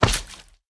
slime_big3.ogg